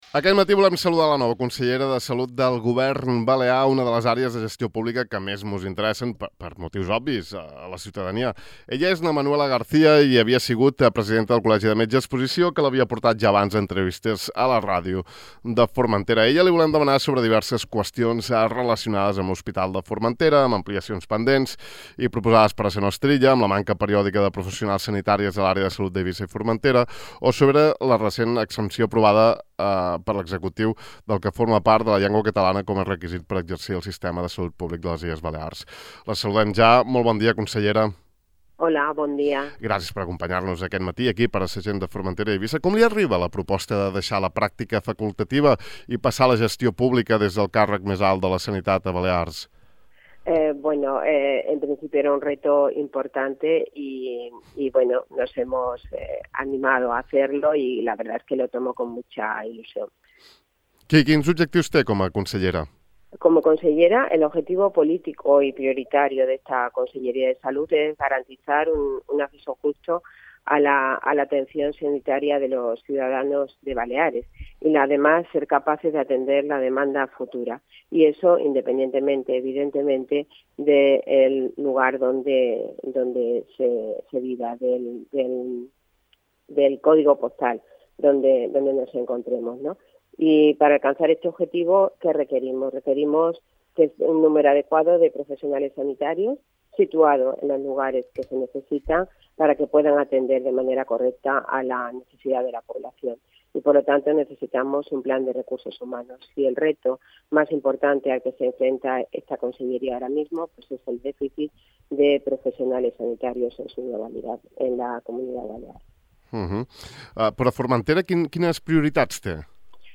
Aquest matí hem entrevistat a la nova consellera de salut del Govern Balear, una de les àrees de gestió política, aquesta, que més interessen, per motius obvis, a la ciutadania.